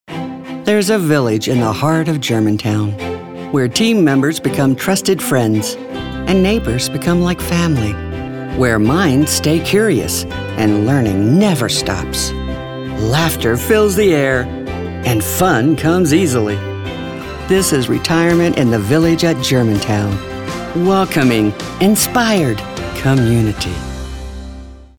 announcer, friendly, genuine, grandmother, sincere, thoughtful, warm